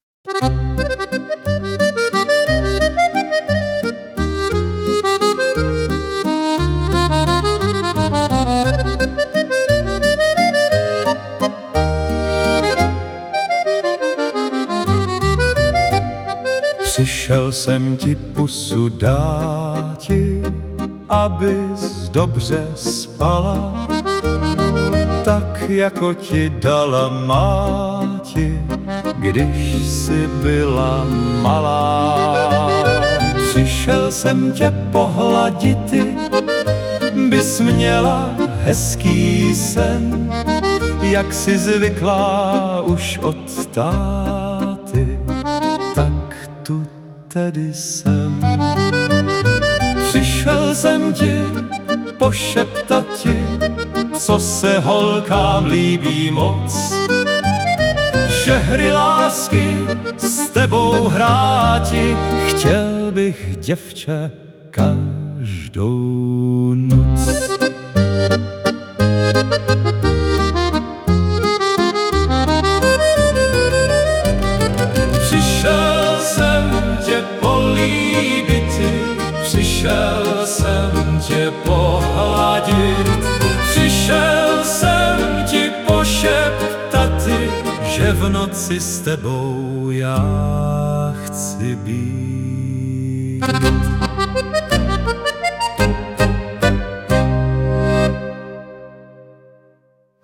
hudba, zpěv: AI
v tónem Francie - i ten polibek sladší je :))
Původně jsem zkoušel ty stolní jednoruč varhany (jedna ruka hraje, druhá obsluhuje měch), ale po poslechu jsem to změnil na varhany "zednické".